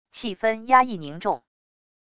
气氛压抑凝重wav音频